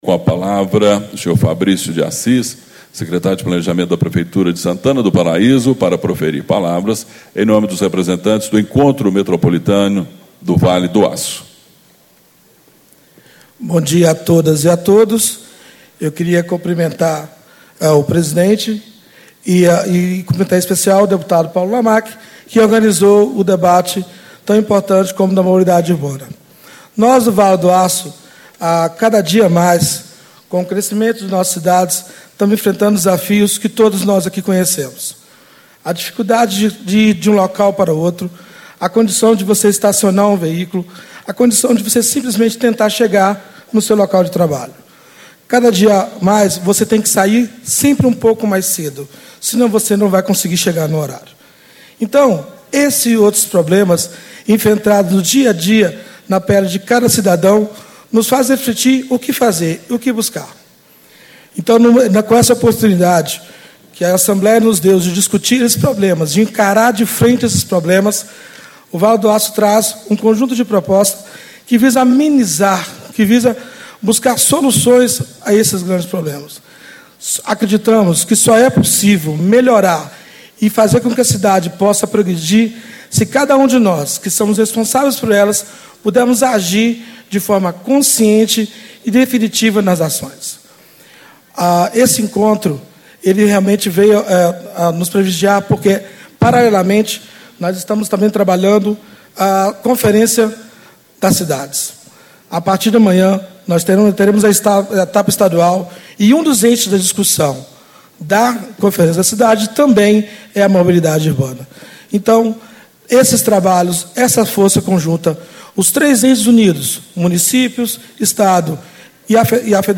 Abertura - Fabrício de Assis - Secretário de Planejamento da Prefeitura de Santana do Paraíso e Representantes do Encontro Metropolitano do Vale do Aço
Discursos e Palestras